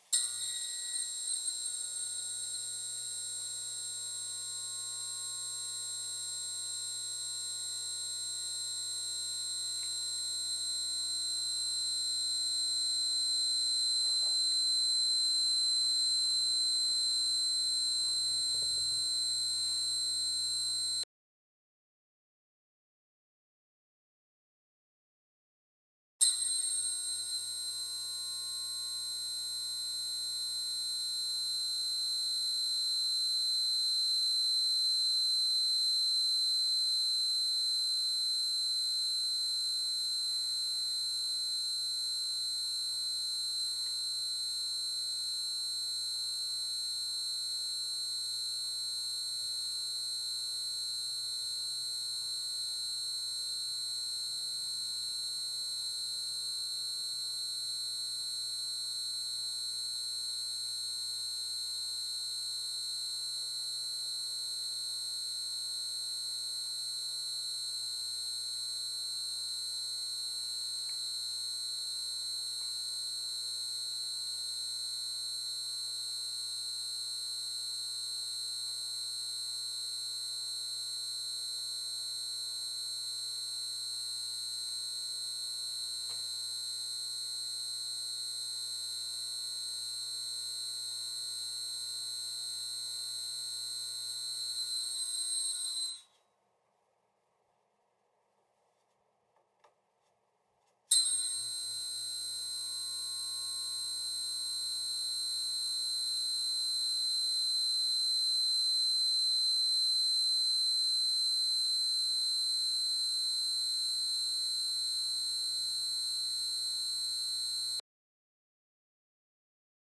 蜜蜂嗡嗡作响
描述：飞入电灯泡的蜂。 放大H6录音机
标签： 翅膀 嗡嗡声 嗡嗡声 昆虫 蜜蜂 电灯泡 自然 OWI 南非洲
声道立体声